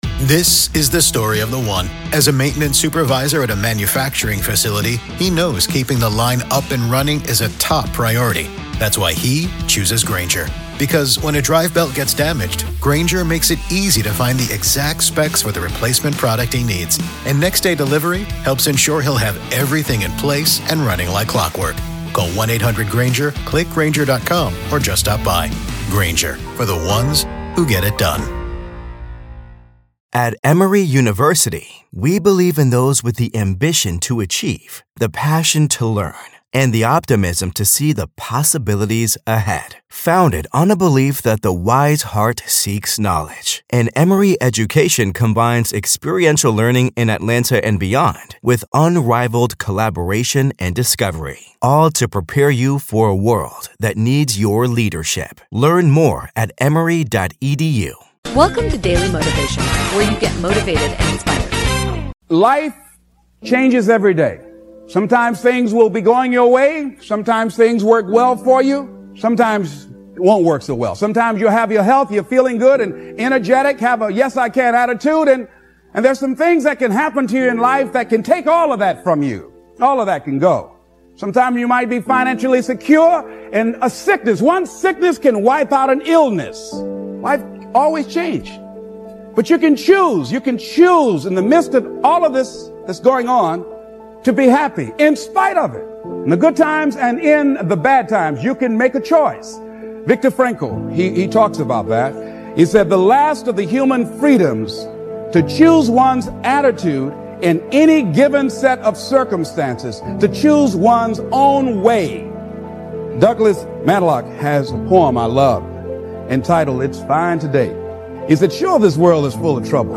Credit to Speaker: Les Brown